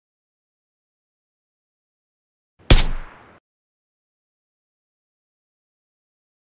Akm sound effect single shot sound effects free download